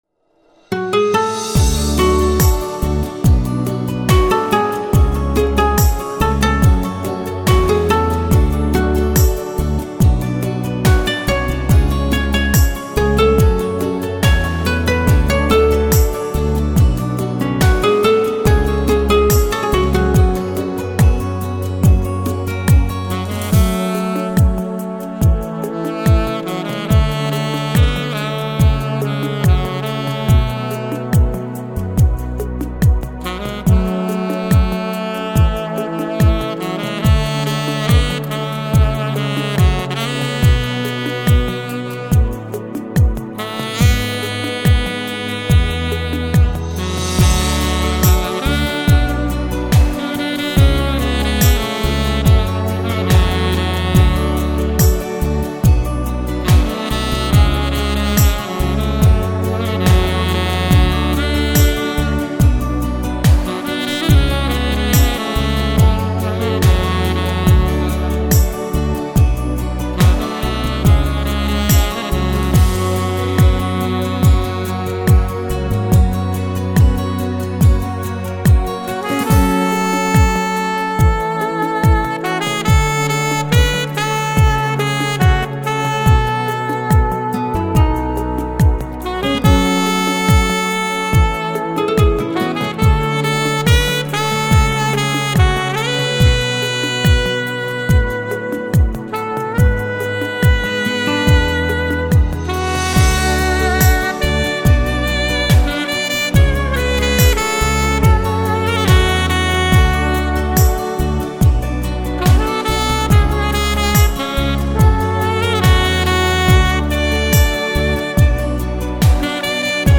Музыка для релаксации 1 вариант mp3